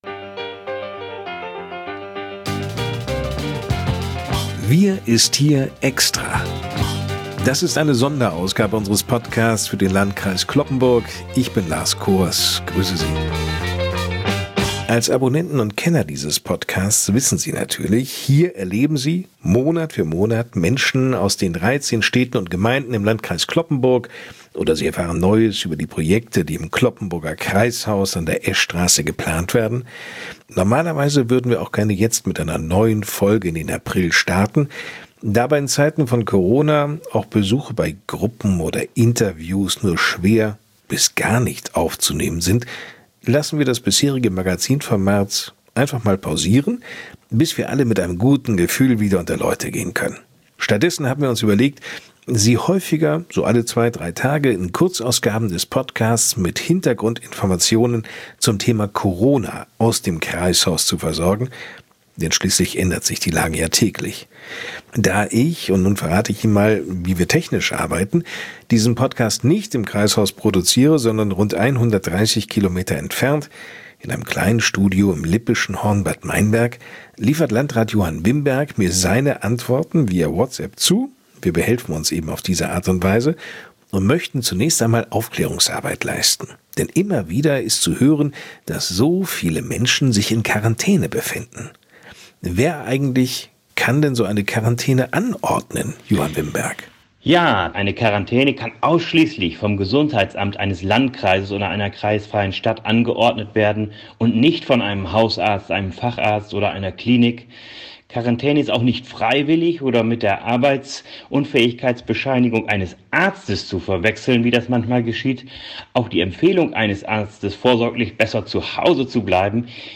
Dabei als Gesprächspartner stets an seiner Seite: Landrat Johann Wimberg.